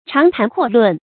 長談闊論 注音： ㄔㄤˊ ㄊㄢˊ ㄎㄨㄛˋ ㄌㄨㄣˋ 讀音讀法： 意思解釋： 猶高談闊論。